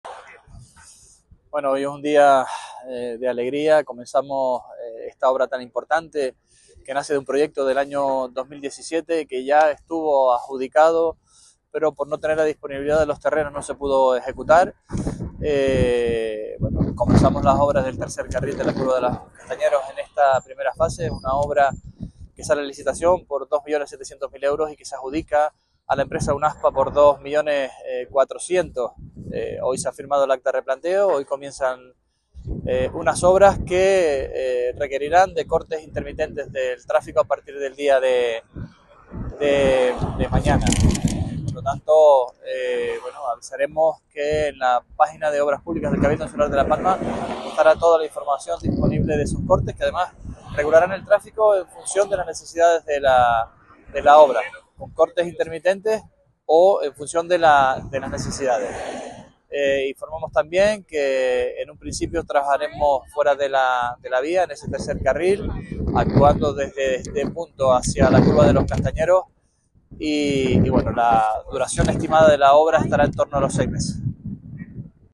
Declaraciones Sergio Rodríguez Curva de Los Castañeros.mp3